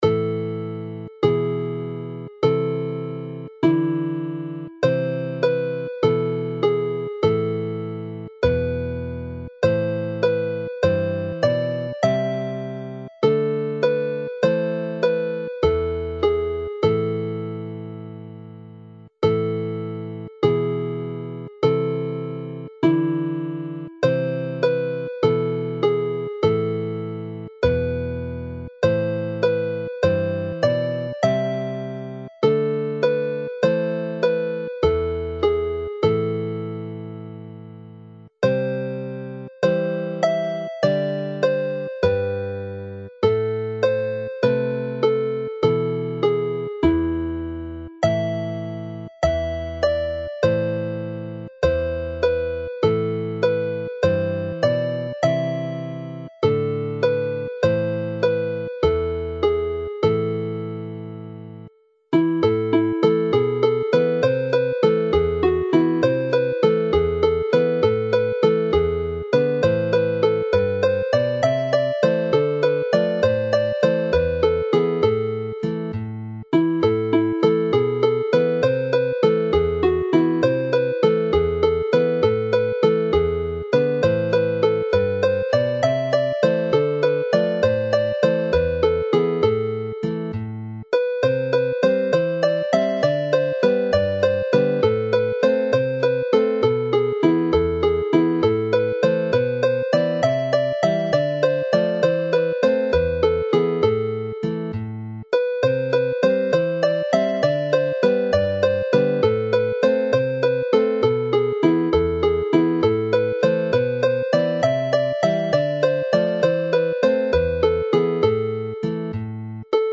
Play the set slowly
This set is founded on the traditional and well-loved Welsh hymn tune Hyder (Confidence / Faith) in a characteristically Welsh minor key.